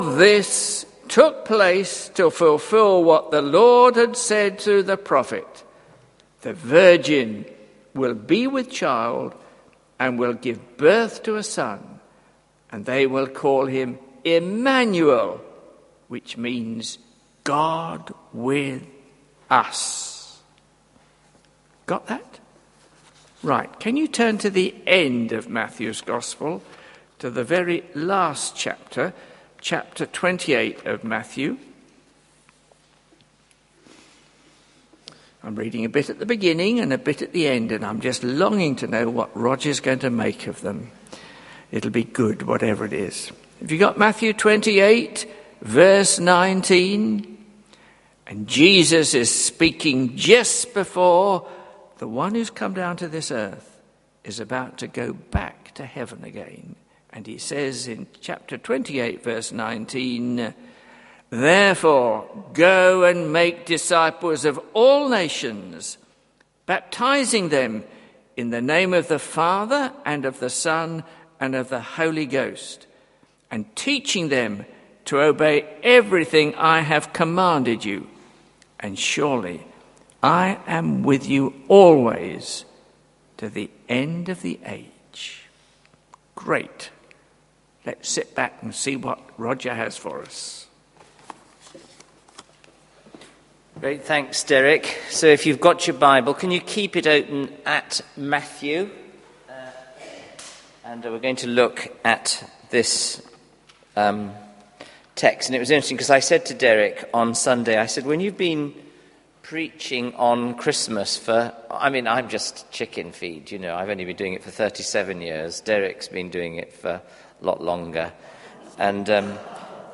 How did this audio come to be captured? Talks from the Faith in the city congregation at St Michael le Belfrey, York, UK